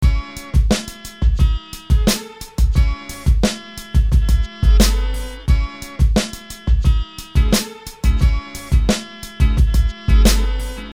Download the Hip Hop Beats